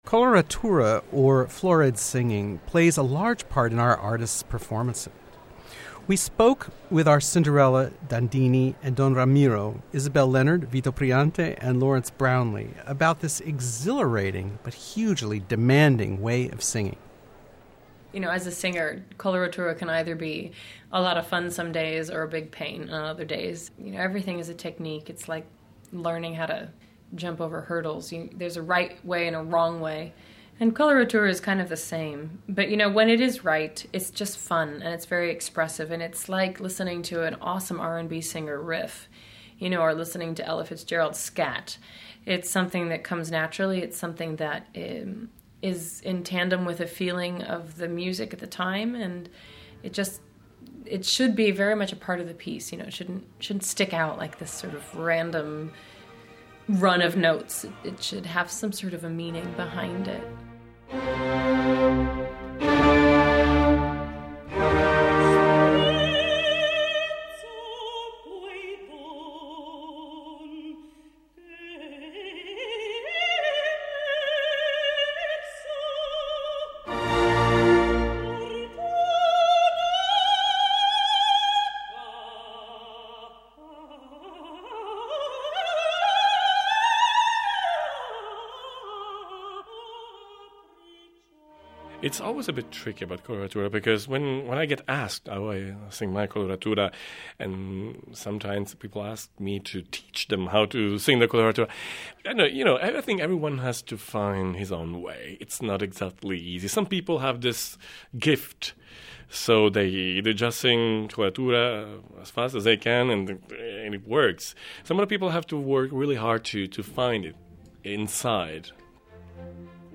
CENERENTOLA cast members.ColoraturaSinging_IsabelLeonard-LawrenceBrownlee-Vito Priante.mp3